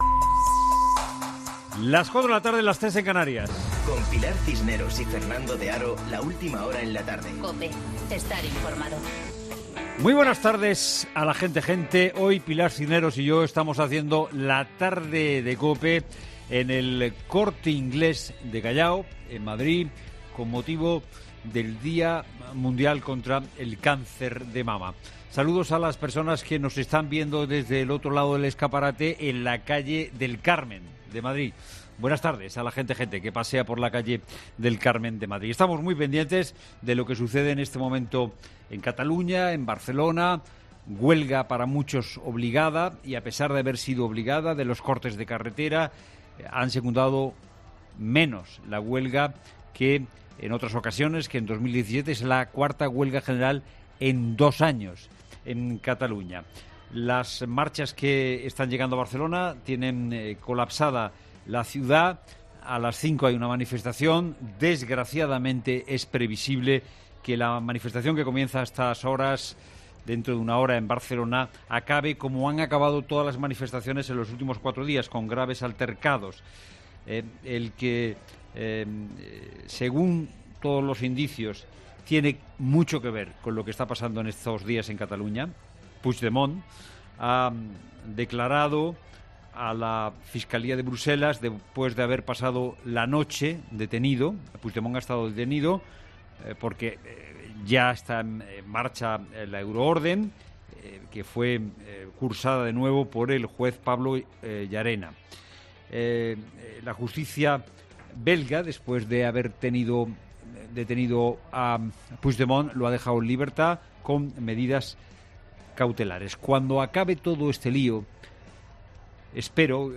Boletín de noticias de COPE del viernes 18 de octubre a las 16 horas